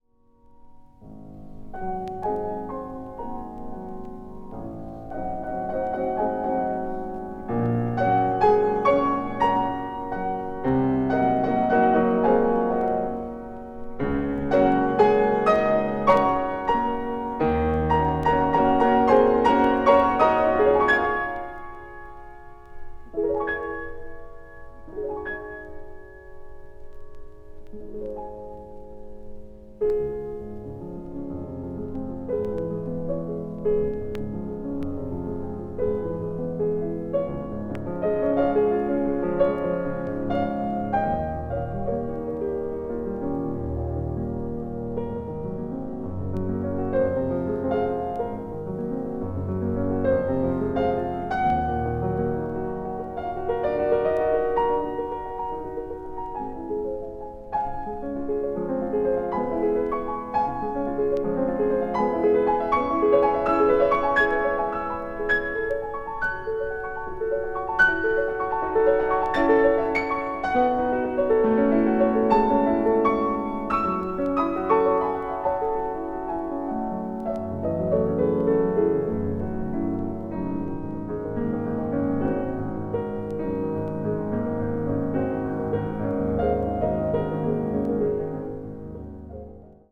classical   modern   piano solo